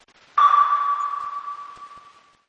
Sonar Submarine sound
(This is a lofi preview version. The downloadable version will be in full quality)
JM_Tesla_Lock-Sound_Sonar_Watermark.mp3